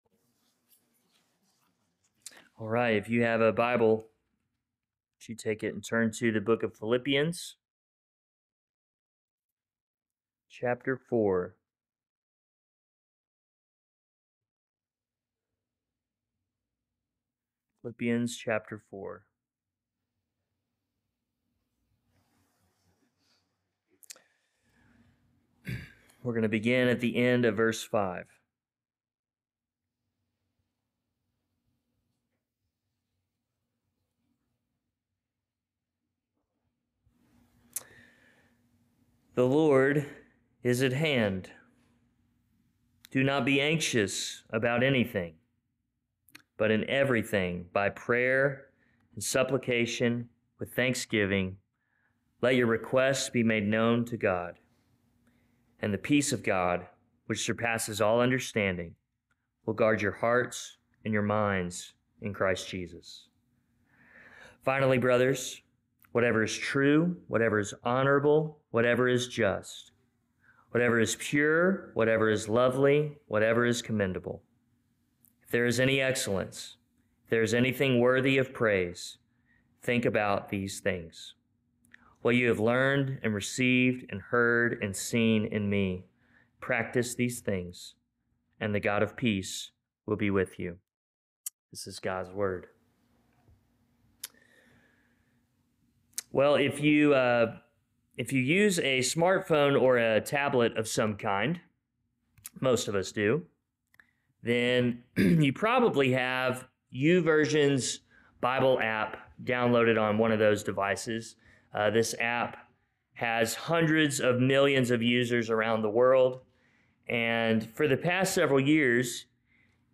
Sermons | Mascot Baptist Church